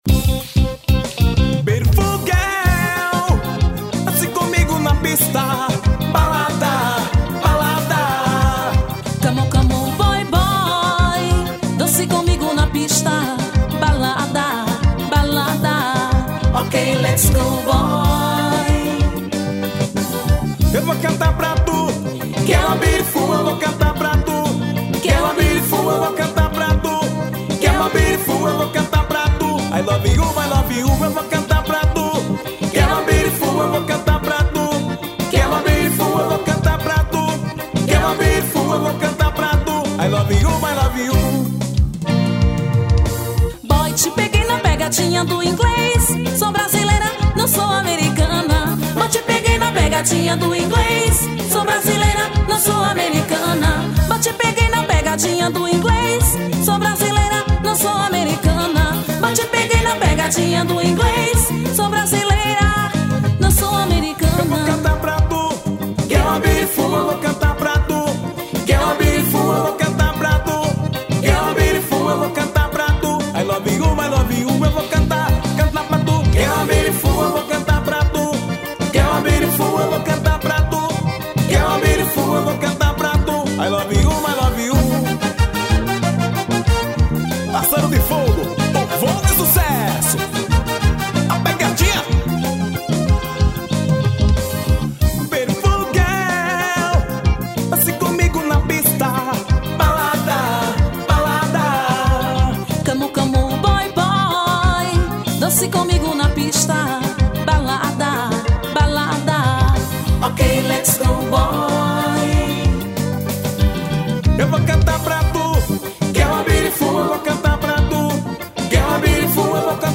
balada.